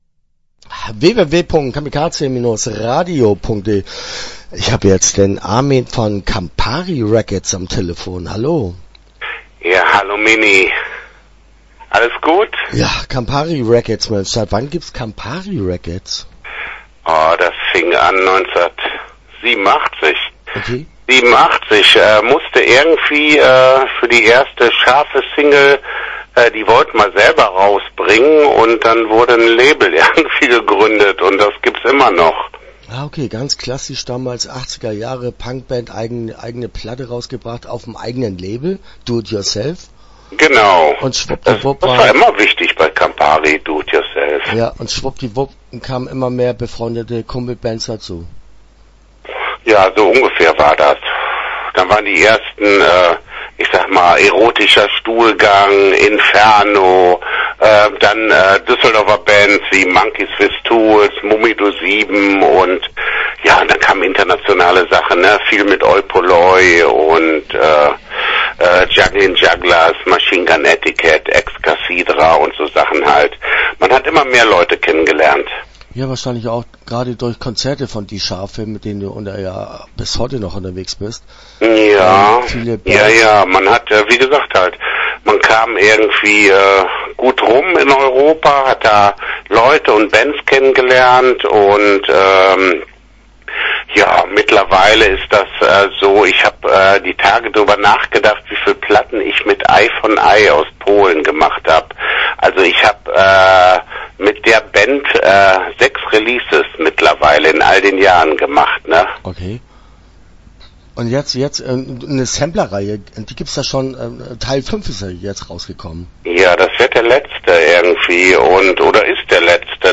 Campary Records - Interview Teil 1 (15:08)